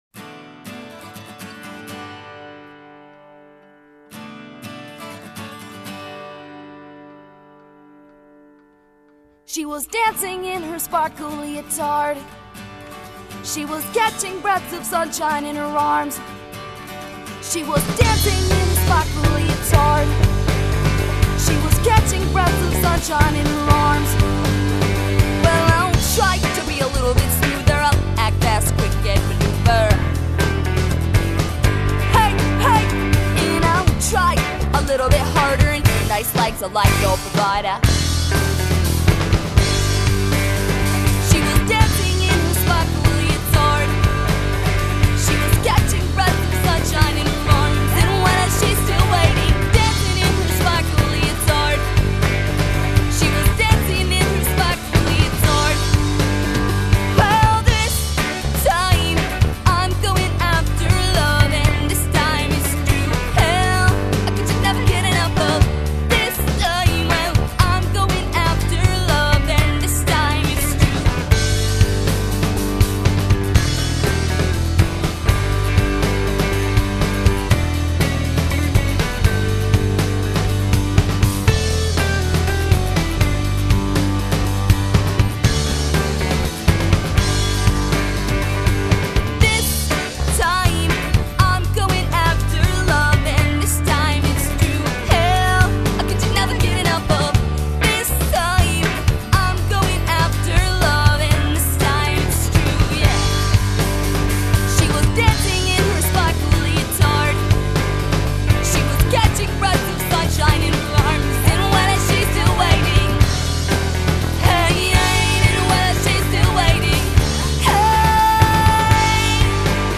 Rock & Roll